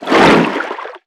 Sfx_creature_snowstalkerbaby_swim_03.ogg